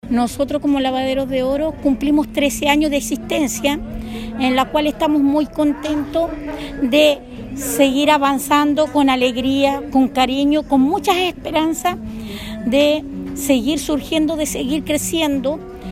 En dependencias de la Delegación Provincial de Choapa se desarrolló la ceremonia de conmemoración del décimo tercer aniversario de los lavaderos de oro de la comuna de Illapel, instancia que junto con dar a conocer la historia de estos trabajadores permitió hablar de los planes de Gobierno que hay en esta materia.